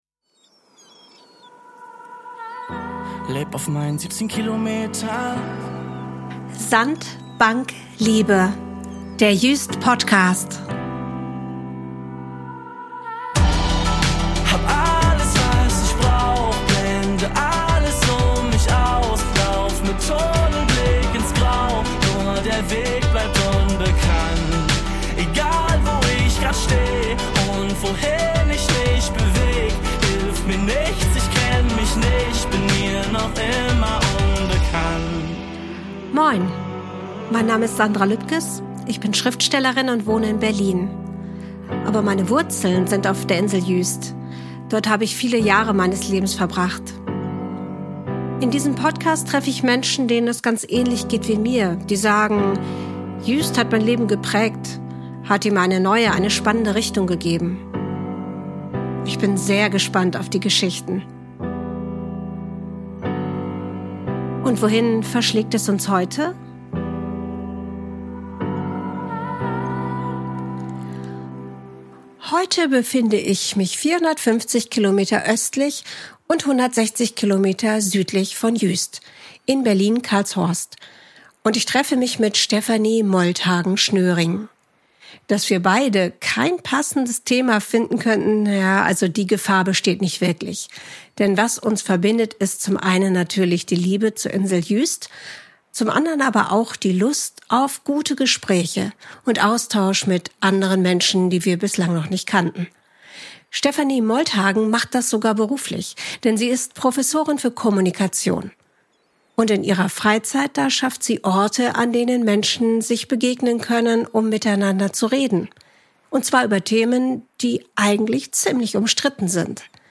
Sprecher:innen: Mitglieder des Kammerchors Bethanien Berlin